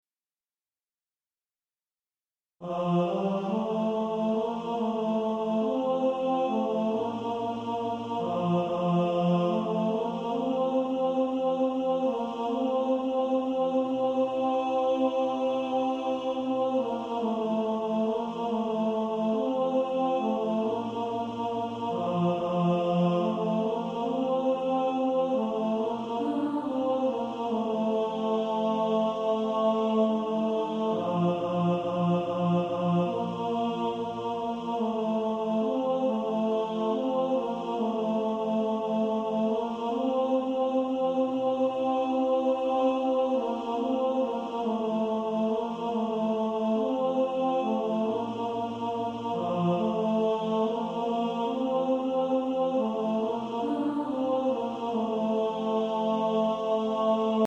Tenor Track.
Practice then with the Chord quietly in the background.